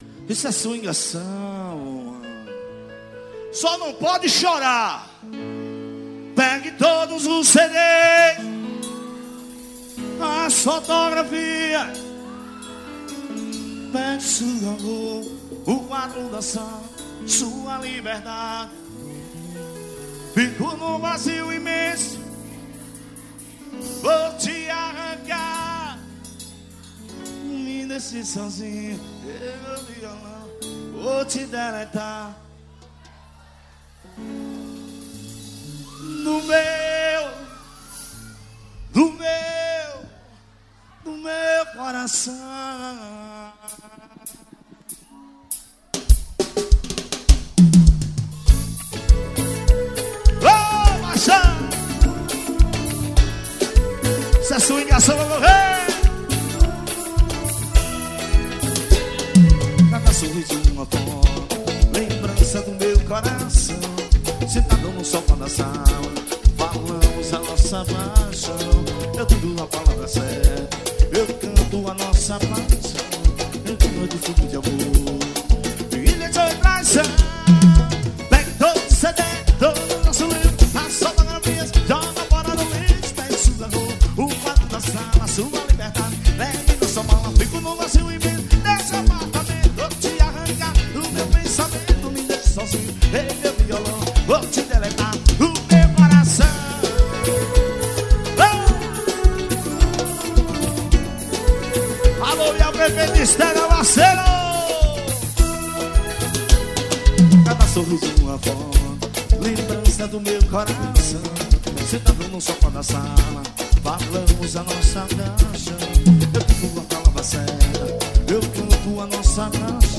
Composição: AXÉ.